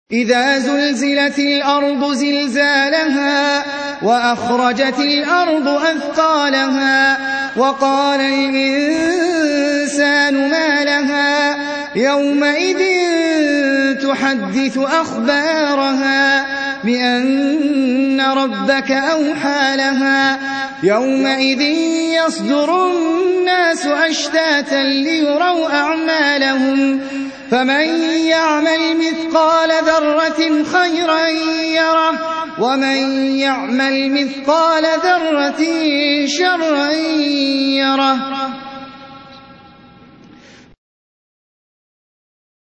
Audio Quran Tarteel Recitation
Surah Sequence تتابع السورة Download Surah حمّل السورة Reciting Murattalah Audio for 99. Surah Az-Zalzalah سورة الزلزلة N.B *Surah Includes Al-Basmalah Reciters Sequents تتابع التلاوات Reciters Repeats تكرار التلاوات